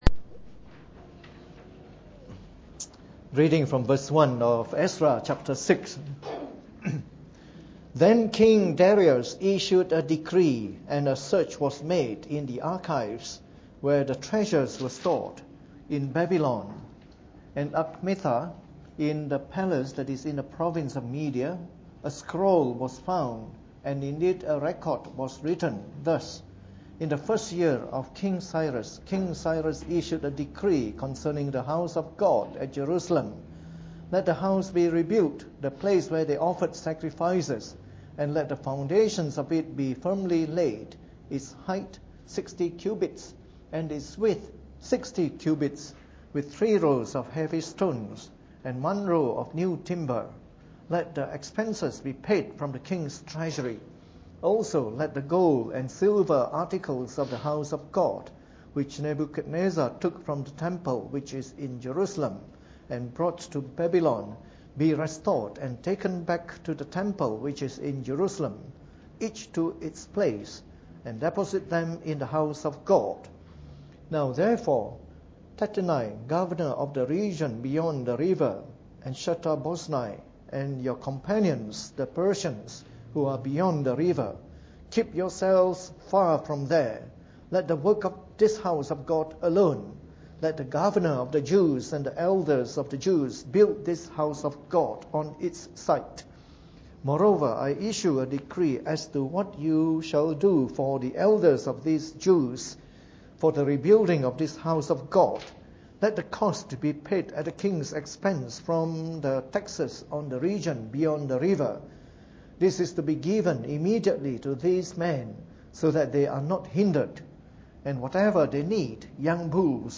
Preached on the 26th of February 2014 during the Bible Study, from our series of talks on the Book of Ezra.